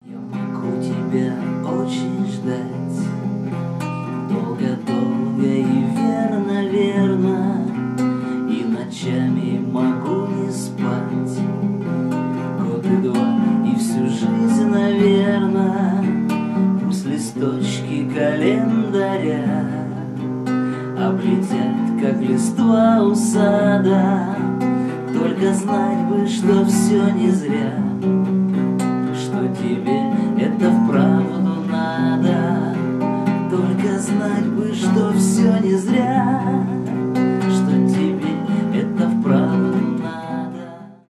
live , лирика
акустика